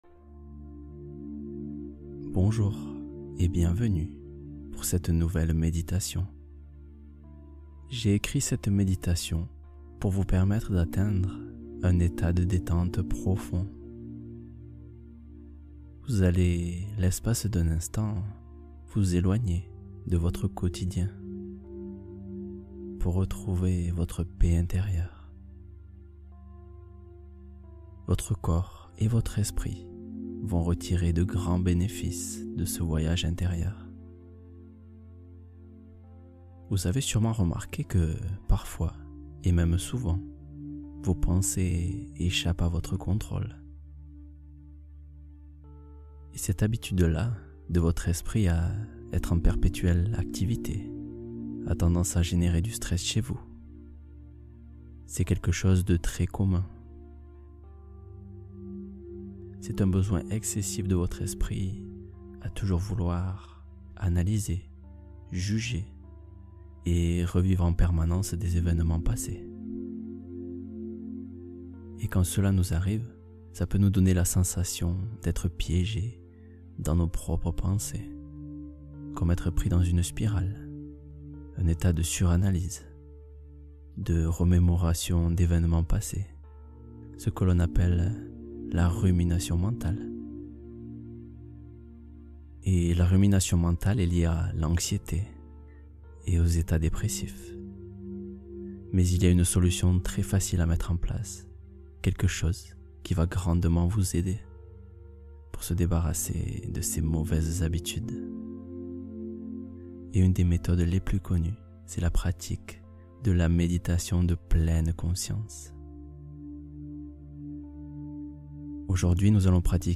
Confiance essentielle révélée — Méditation intense d’alignement